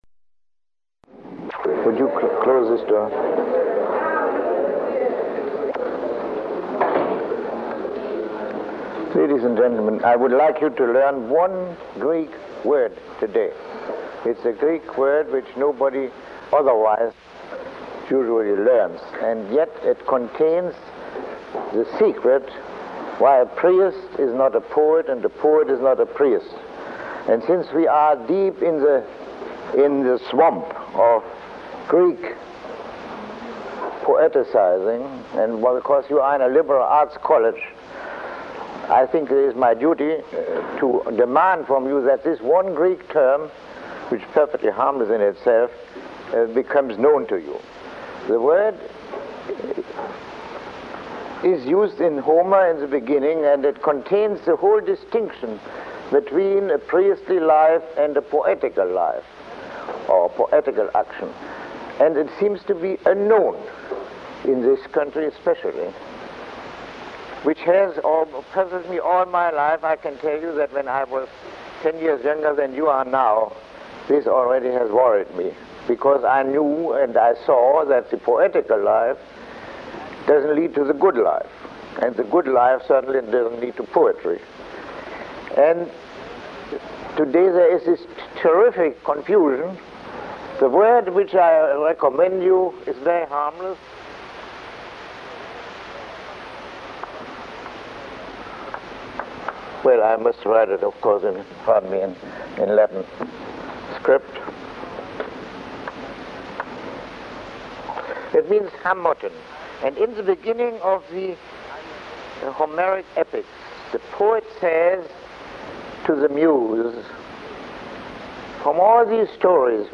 Lecture 12